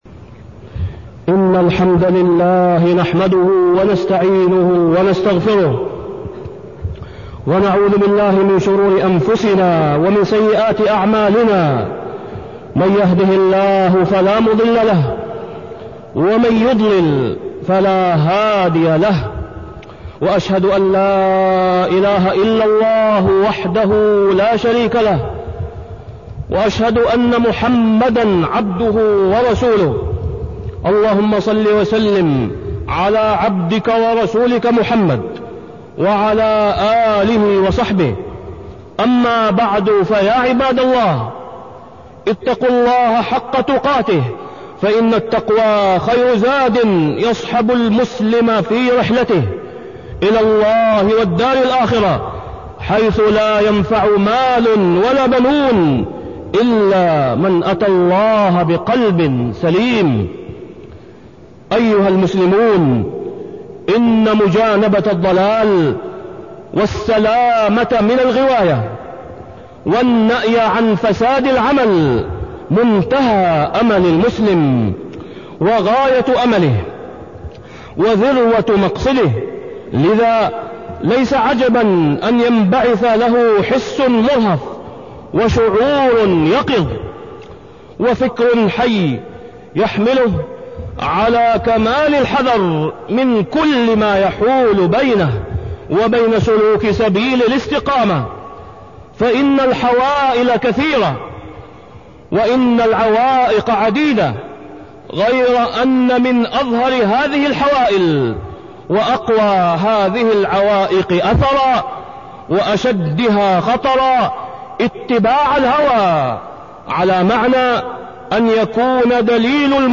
تاريخ النشر ١ جمادى الآخرة ١٤٢٤ هـ المكان: المسجد الحرام الشيخ: فضيلة الشيخ د. أسامة بن عبدالله خياط فضيلة الشيخ د. أسامة بن عبدالله خياط اتباع الهوى